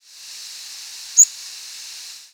Magnolia Warbler diurnal
presumed Magnolia Warbler nocturnal flight calls